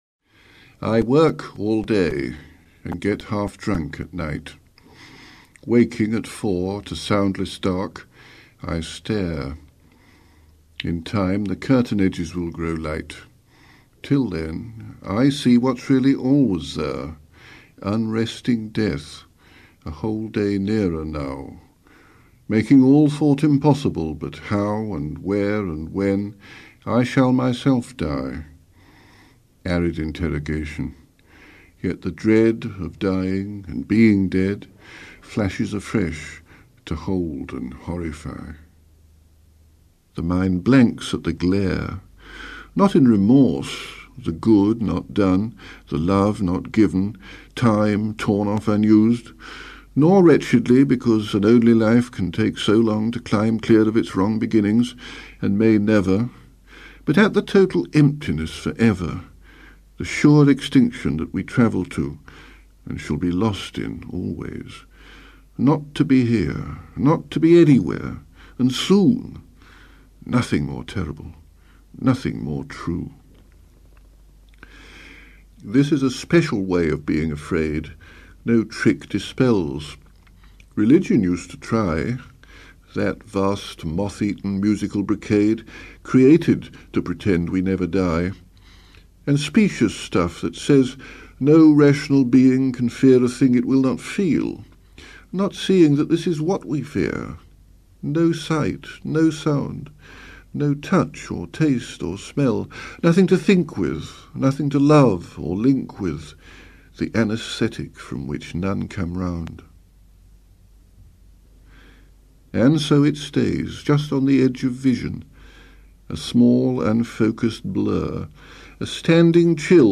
Click here to hear Larkin reading the great Aubade.